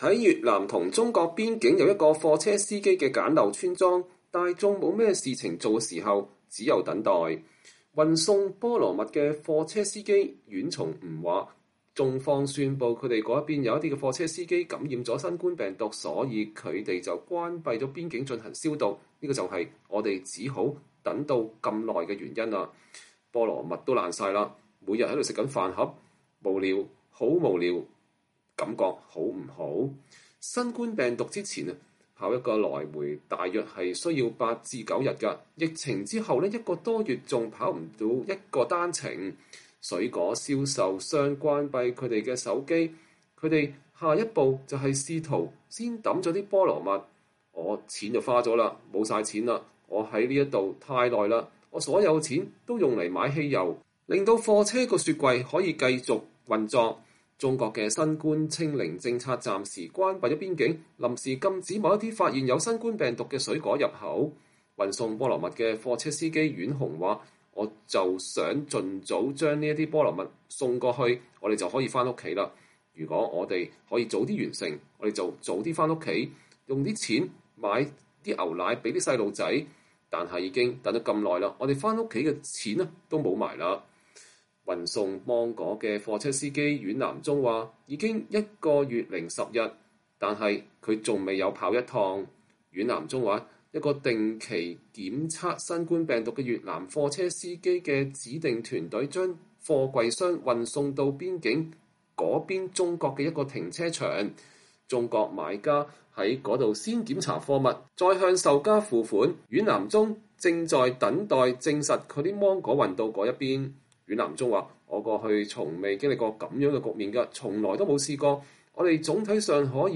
美國之音在東南亞的電視記者過去幾個月來對農民和貨車司機等相關人士進行了交談。這是他們的報導。